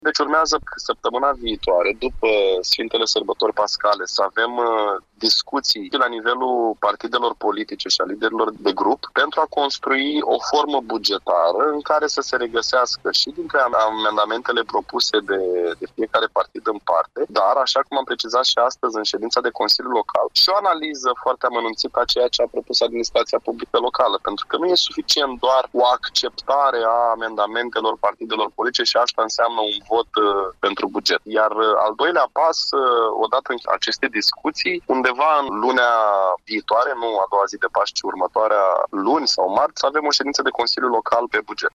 Liderul grupului social democrat din consiliul local Iaşi, Bogdan Balanişcu a adăugat că din partea partidelor au venit multe solicitări, dar trebuie analizată oportunitatea susţinerii financiare a acestora.